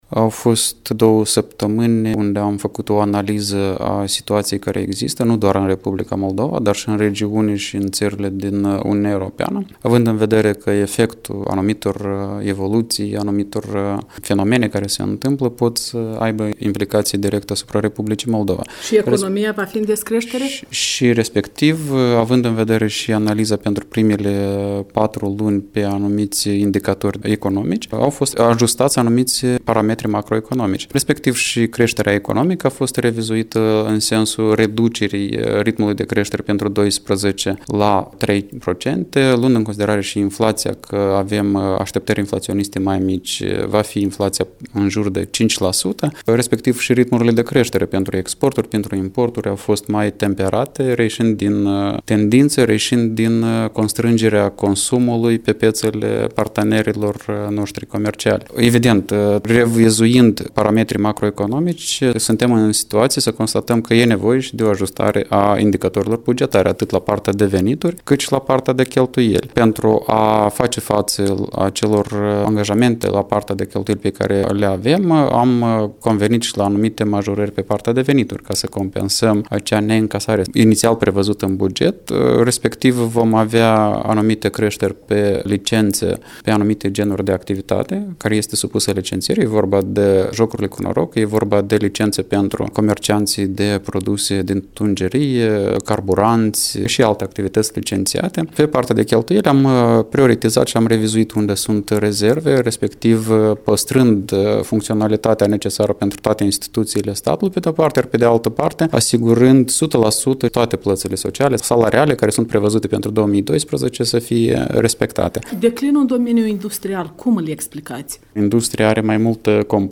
Interviu cu ministrul de finanțe Veaceslav Negruță (versiune integrală)